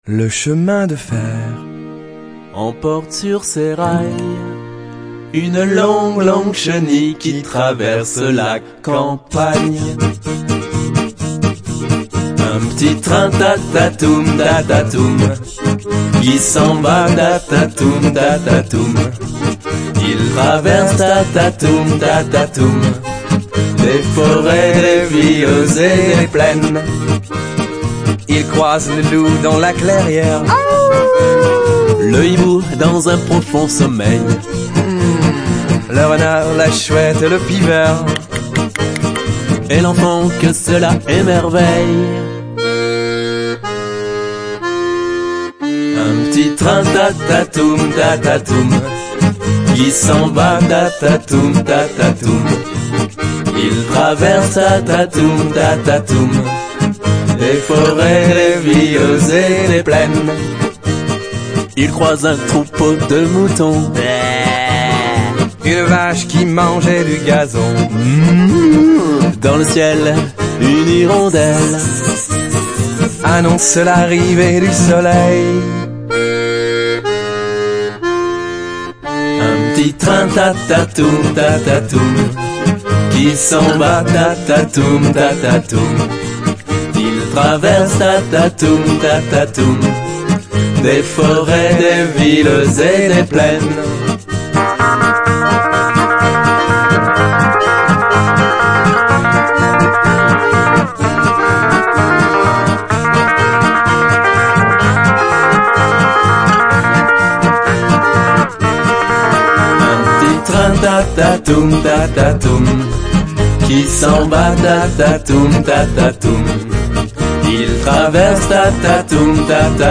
Éveillons-nous en mimant, chantant et dansant